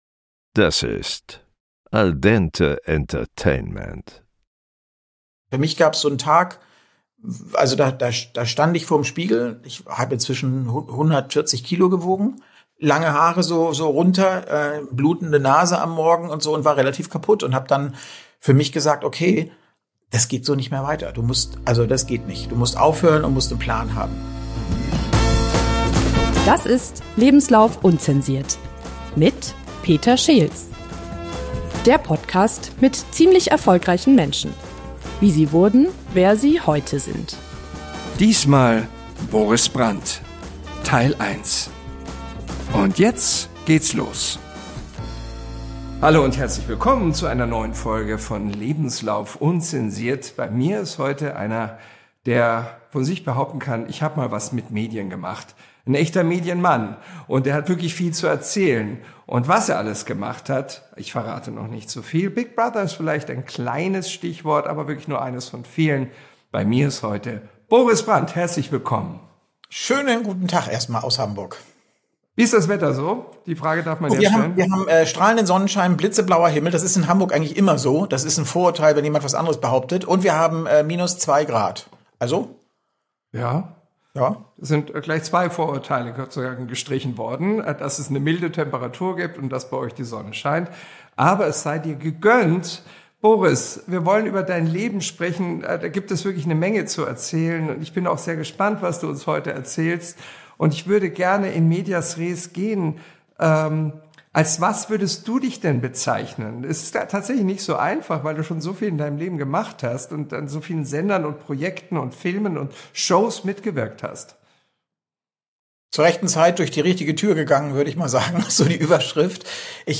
Von der Vermarktung großer Hollywood-Blockbuster bis hin zur Zusammenarbeit mit Falco und Depeche Mode – ein Gespräch über Handwerk, Disziplin und das Wissen, wann Schluss ist.